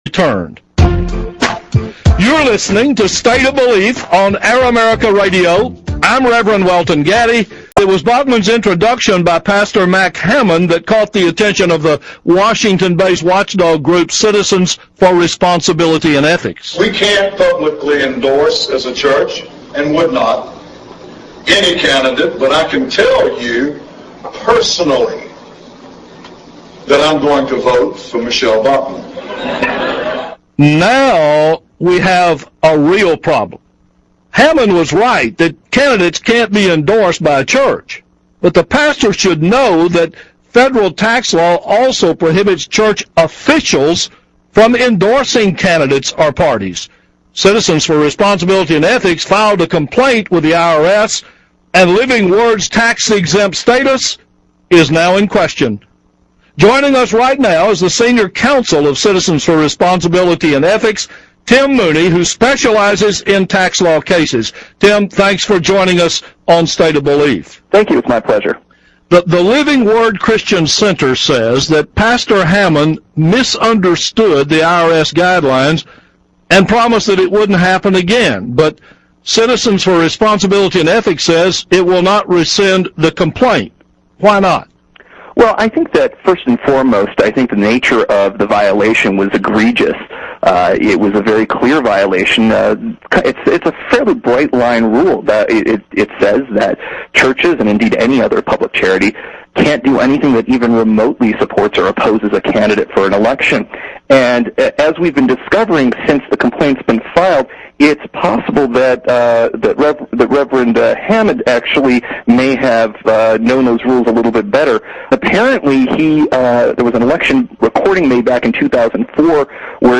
I was interviewed for Air America's State of Belief recently... feel free to listen